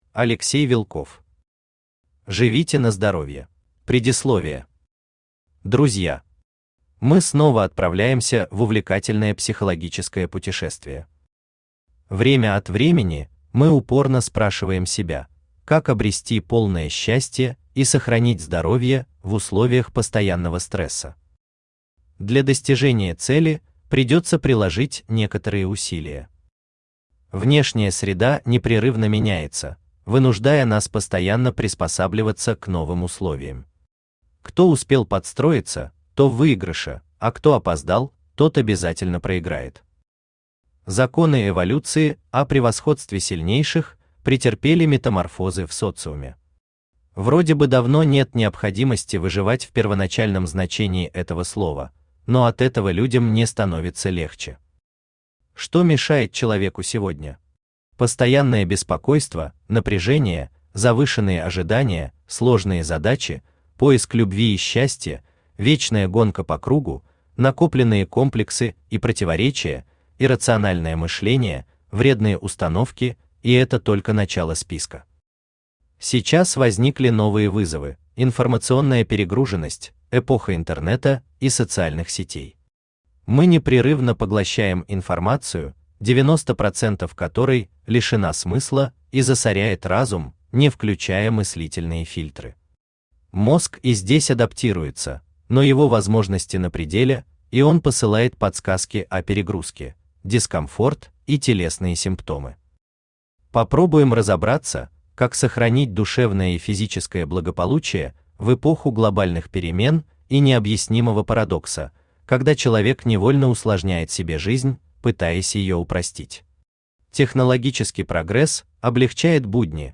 Аудиокнига Живите на здоровье | Библиотека аудиокниг
Aудиокнига Живите на здоровье Автор Алексей Сергеевич Вилков Читает аудиокнигу Авточтец ЛитРес.